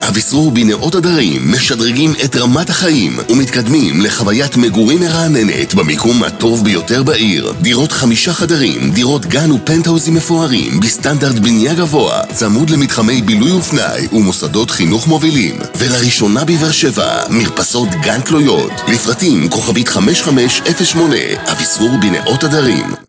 תשדירי רדיו לדוגמה